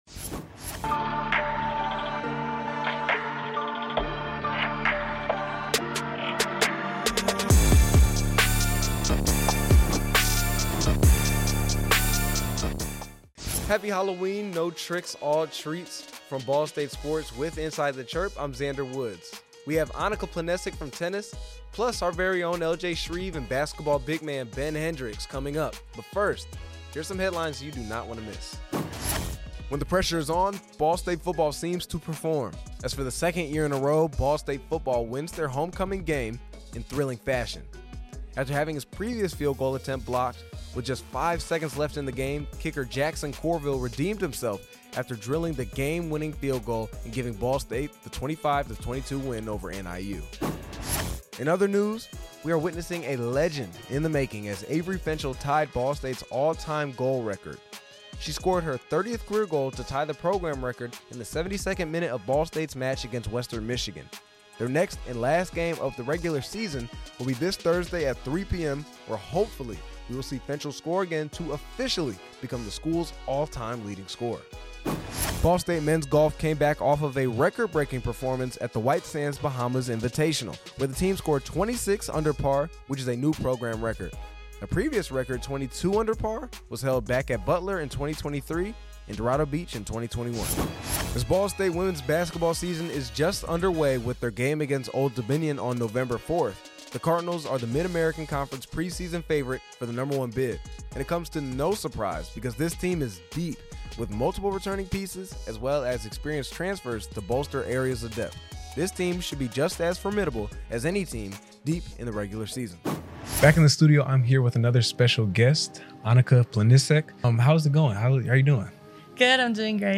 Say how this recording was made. It’s a Halloween edition of Inside The Chirp from the Betsy M. Ross studios. Women’s Tennis, Men’s Basketball and the latest headlines from Ball State Sports.